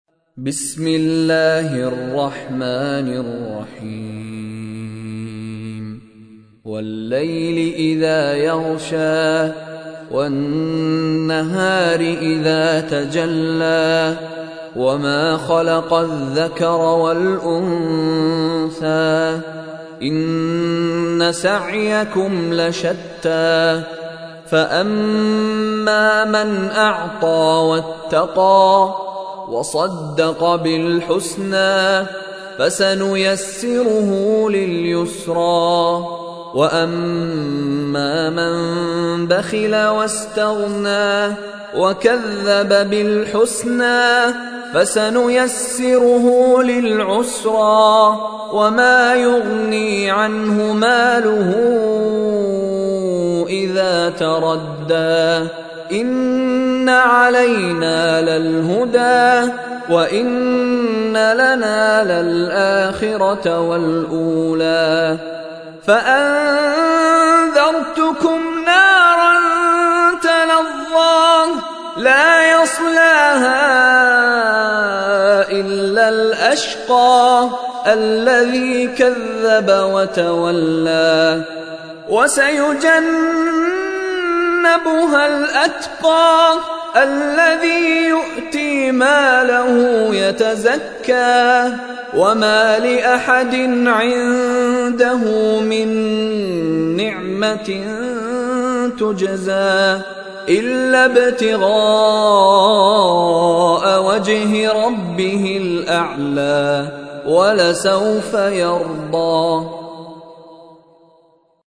কোরআন তেলাওয়াত